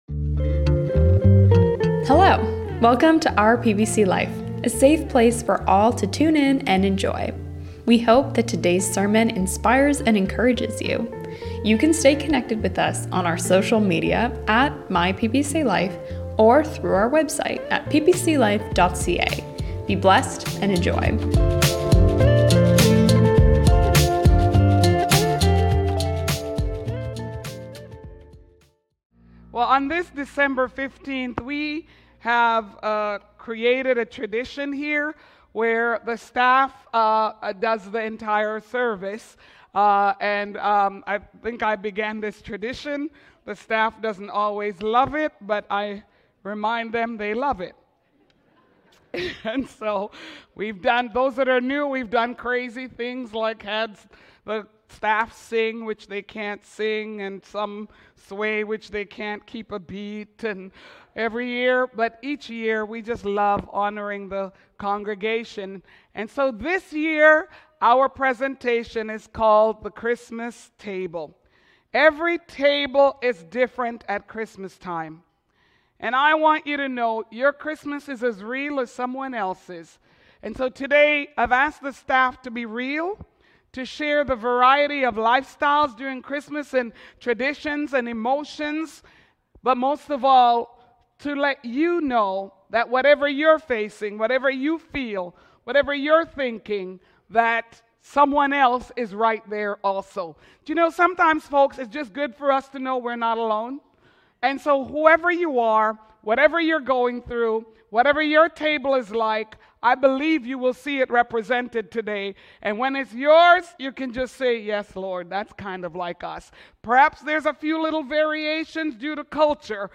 My Christmas Table (Staff Presentation)
Tune in to hear all about the PPC staff's Christmas traditions, the old, the new, the happy, the funny, the real. Don't miss this special service!